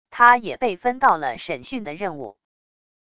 他也被分到了审讯的任务wav音频生成系统WAV Audio Player